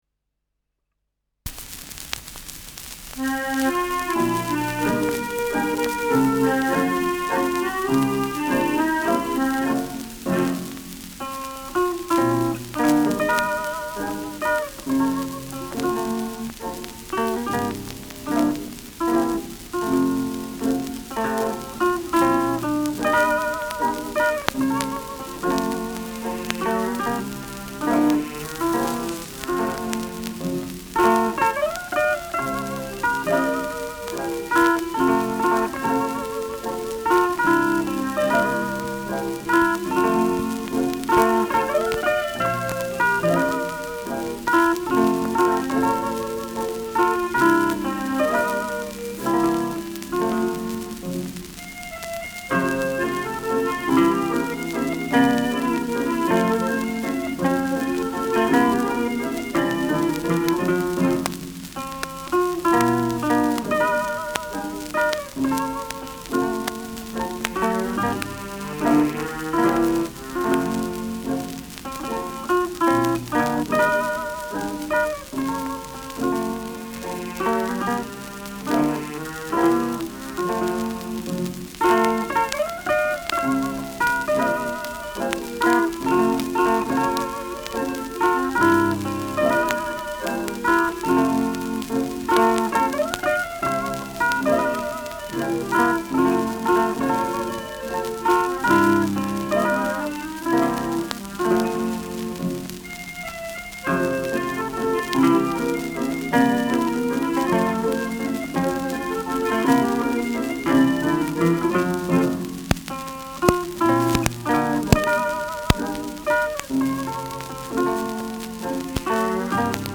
Schellackplatte
präsentes Rauschen : leichtes Knistern
[Berlin] (Aufnahmeort)
Stubenmusik* FVS-00016